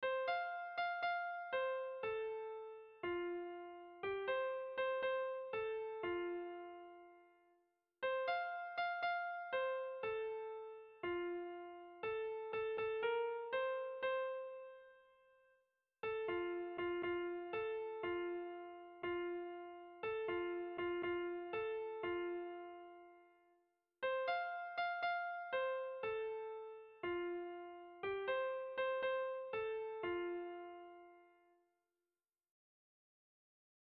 Kontakizunezkoa
Zortziko txikia (hg) / Lau puntuko txikia (ip)